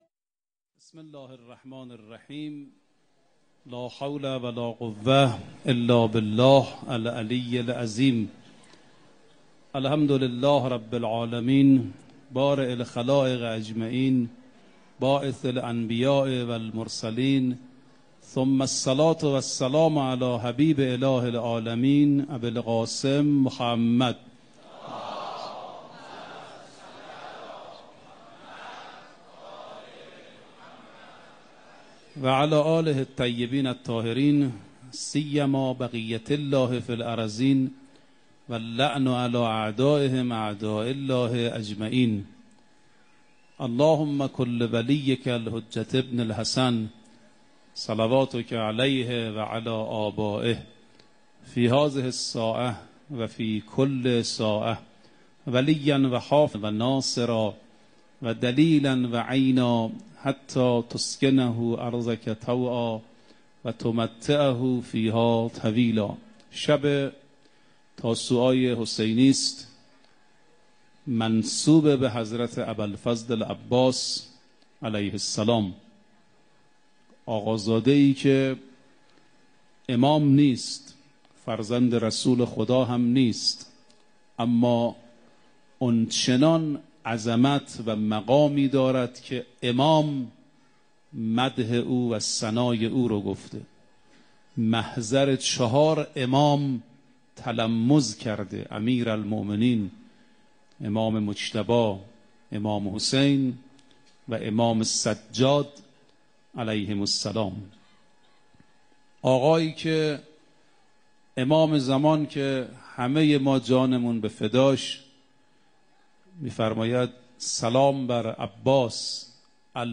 سخنرانی
در شب هشتم ماه محرم در حسینیه امام خمینی